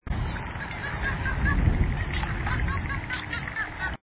WAV_0037_001（飛旋海豚）.mp3
長吻飛旋海豚 Stenella longirostris
花蓮縣 花蓮市 太平洋
錄音環境 太平洋